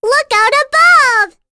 Luna-Vox_Skill2.wav